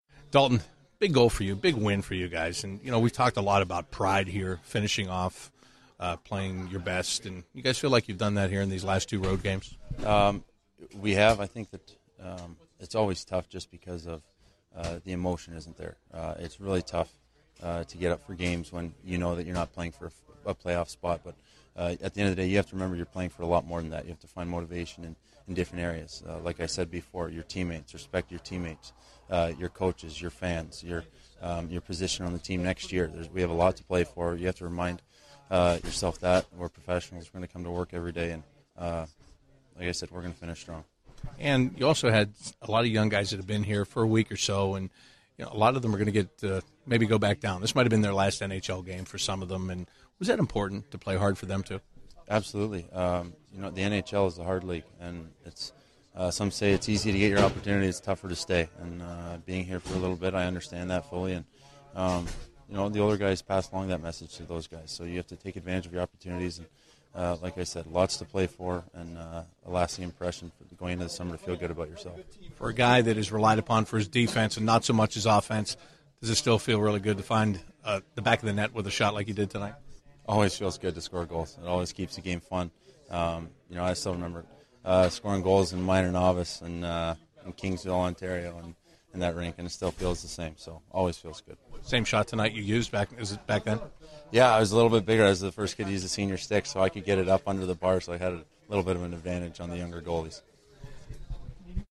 Dalton Prout Post-Game 04/08/16
An episode by CBJ Interviews